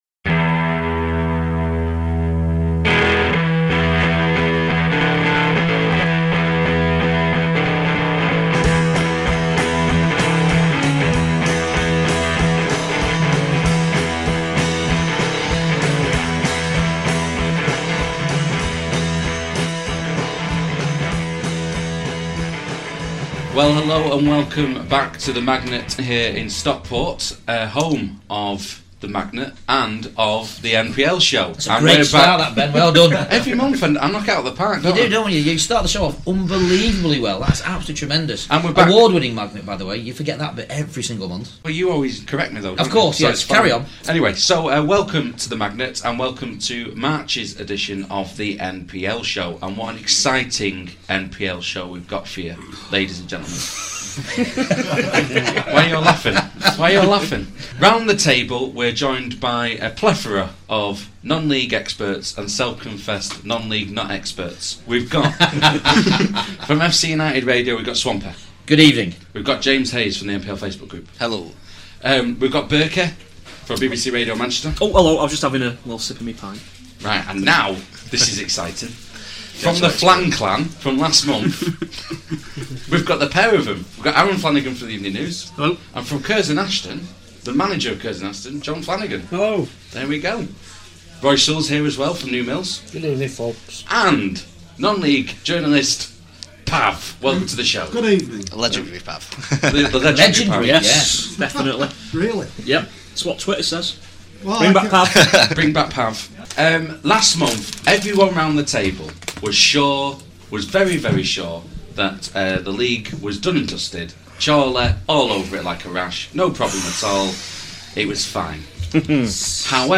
With Thanks: This programme was recorded at the Magnet in Stockport on Wednesday 12th March 2014.